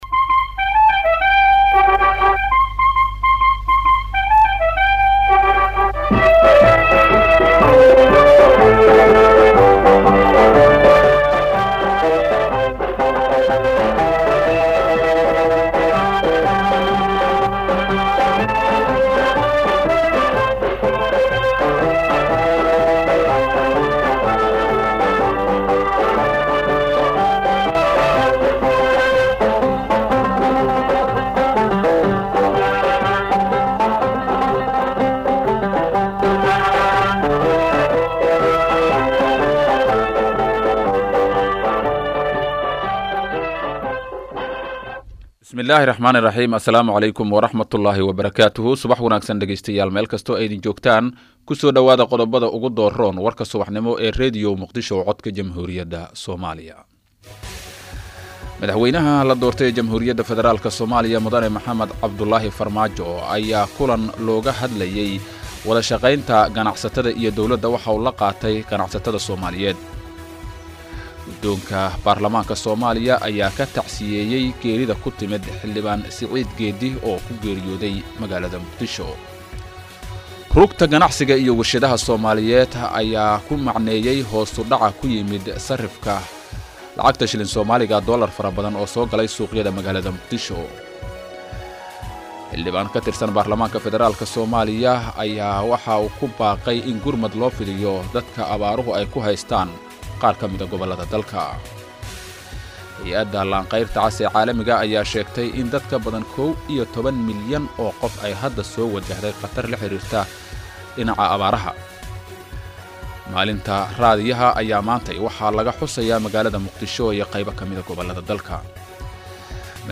Dhageyso Warka Subax ee Radio Muqdisho.